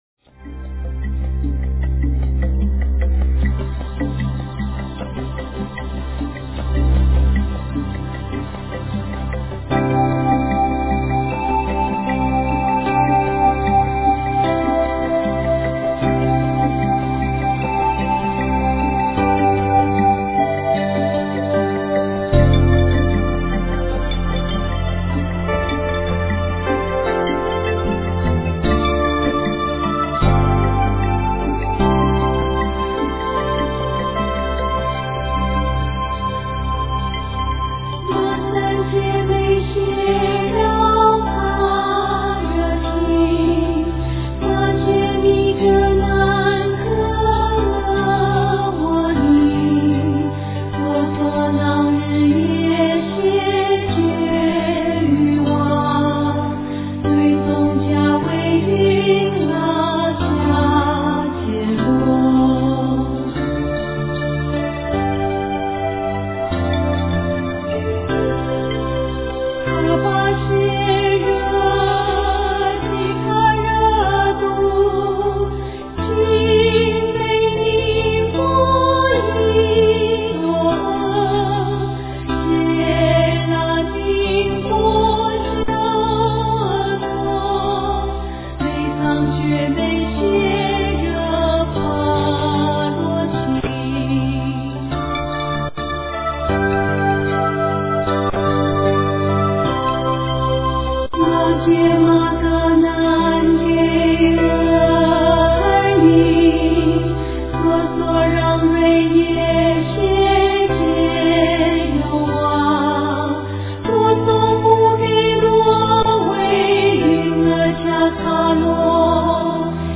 般若波罗密多心经 -藏音 诵经 般若波罗密多心经 -藏音--新韵传音 点我： 标签: 佛音 诵经 佛教音乐 返回列表 上一篇： 心经-诵读 下一篇： 大悲咒-念诵 相关文章 清静法身佛 II--圆满自在组 清静法身佛 II--圆满自在组...